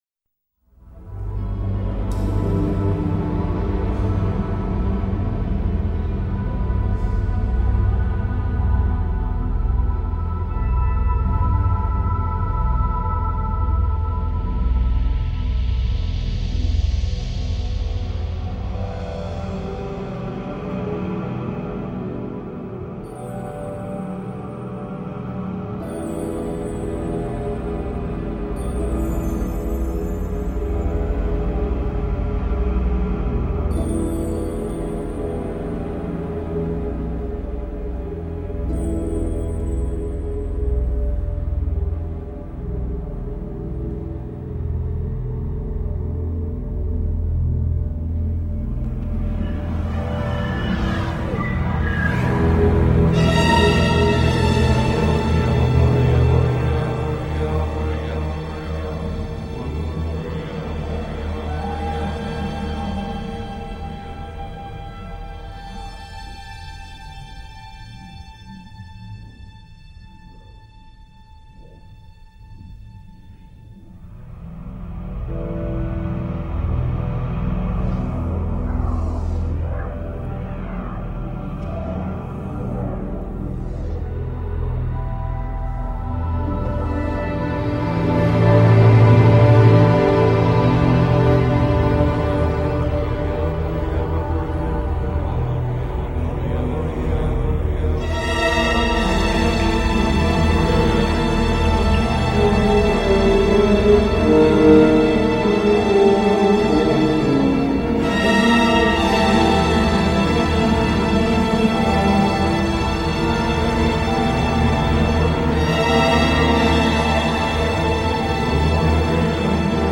◆ 音乐类别:电影原声
一段夹杂颂经、呼喊、重低音电子音乐的管弦乐，表达剧中人物忿怒又无奈的心情。